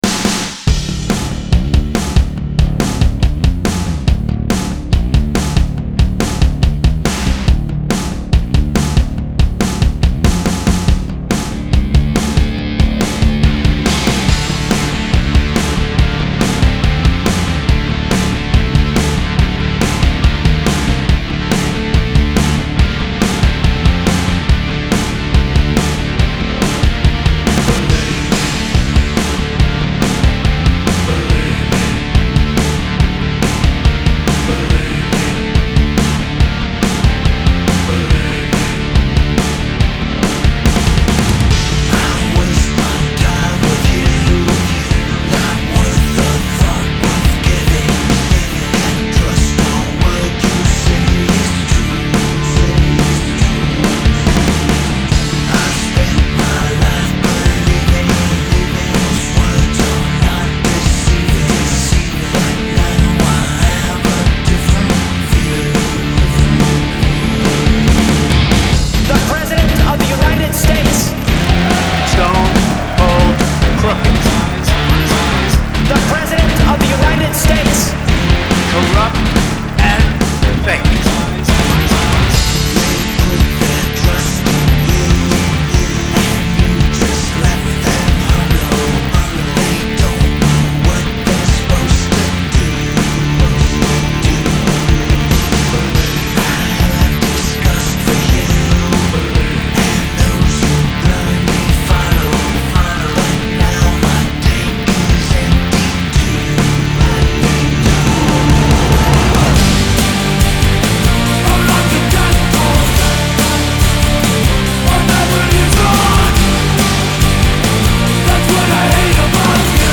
Genre : Rock